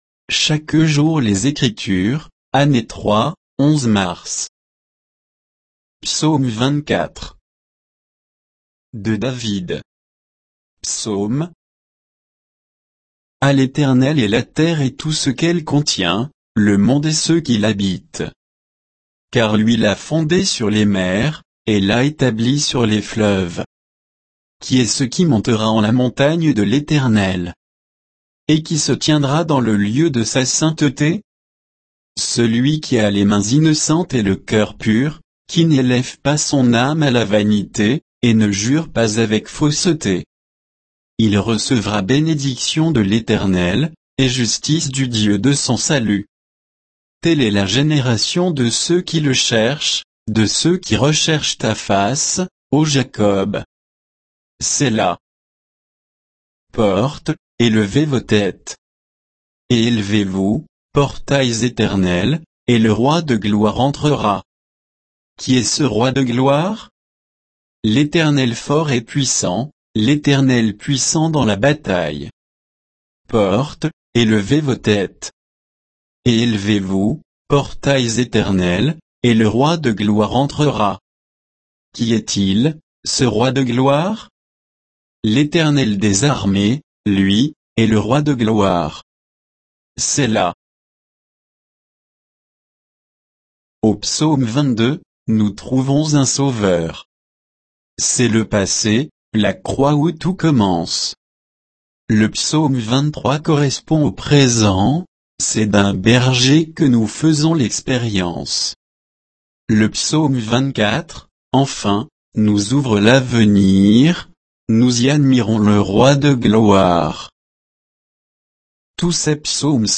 Méditation quoditienne de Chaque jour les Écritures sur Psaume 24